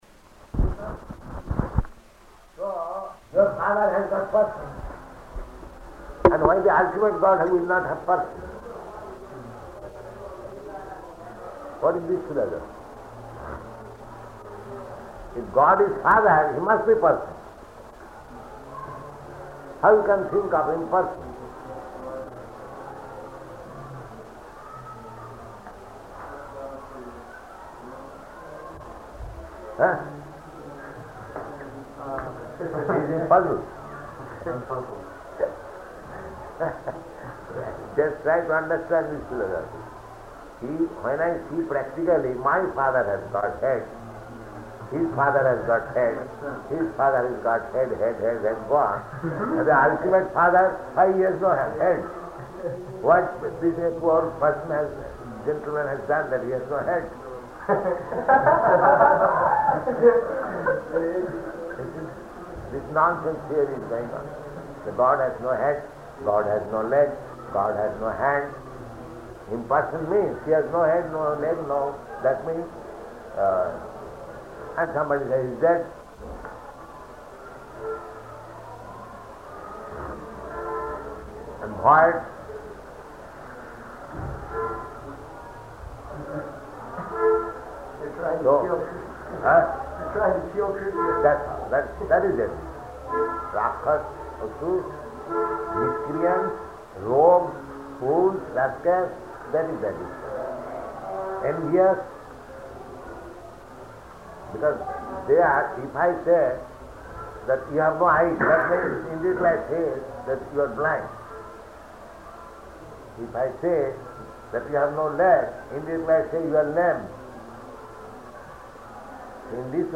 Lecture in Room
Lecture in Room --:-- --:-- Type: Lectures and Addresses Dated: December 21st 1970 Location: Surat Audio file: 701221LE-SURAT.mp3 Prabhupāda: So your father has got substance.